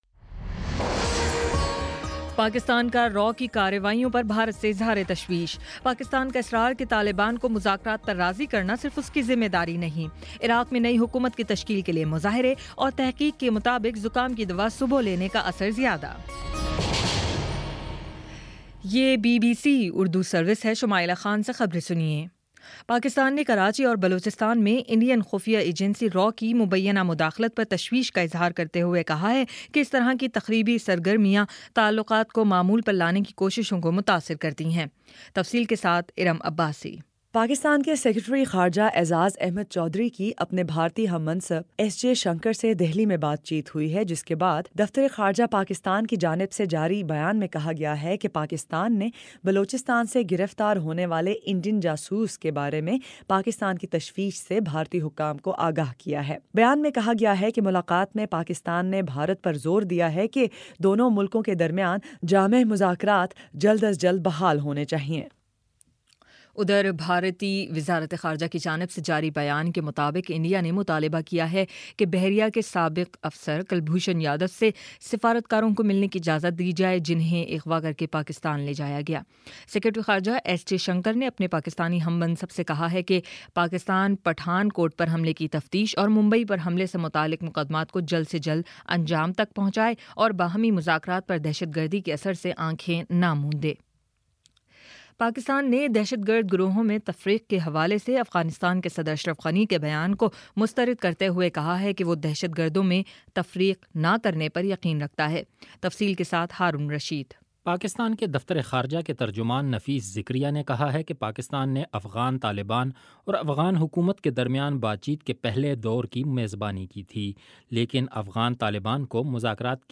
اپریل 26 : شام چھ بجے کا نیوز بُلیٹن